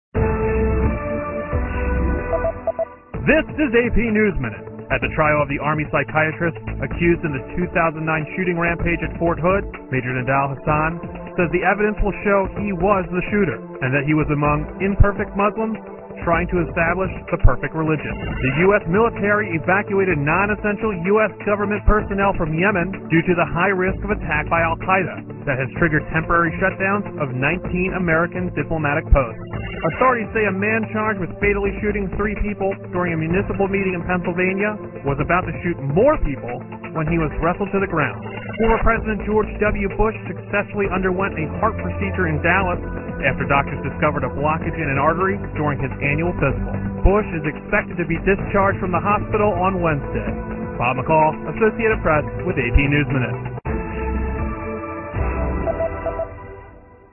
在线英语听力室美联社新闻一分钟 AP 2013-08-10的听力文件下载,美联社新闻一分钟2013,英语听力,英语新闻,英语MP3 由美联社编辑的一分钟国际电视新闻，报道每天发生的重大国际事件。电视新闻片长一分钟，一般包括五个小段，简明扼要，语言规范，便于大家快速了解世界大事。